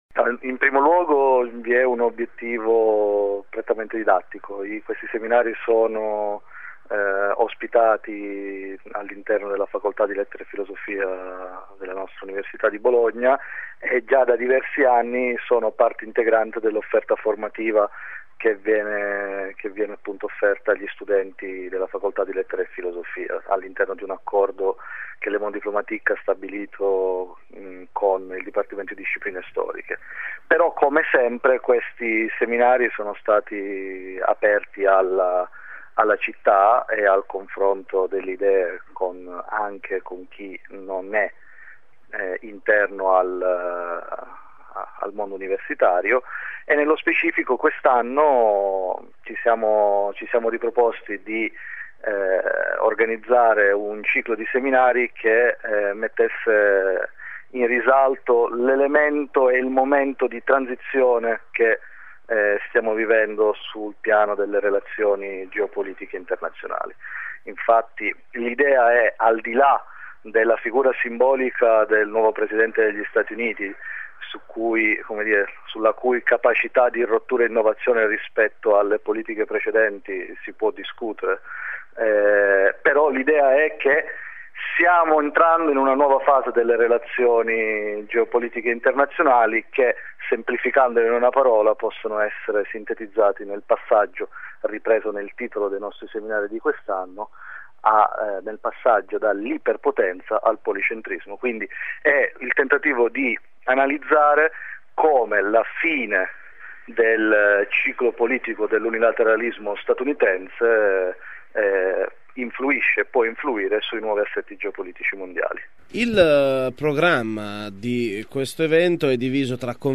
interv1.mp3